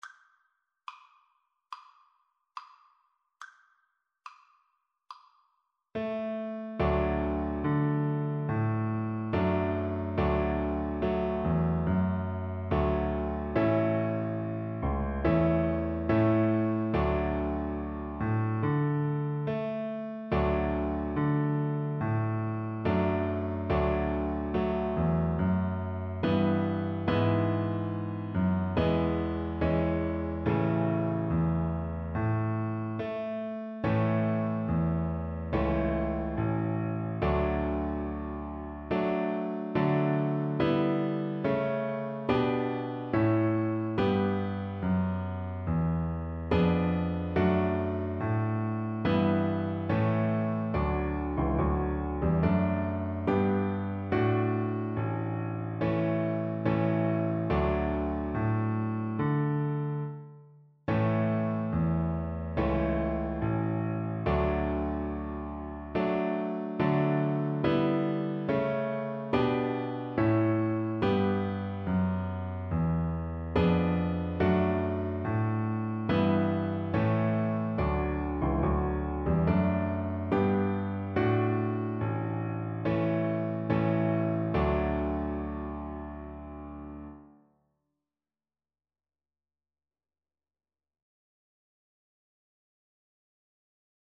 Cello
4/4 (View more 4/4 Music)
D major (Sounding Pitch) (View more D major Music for Cello )
Moderato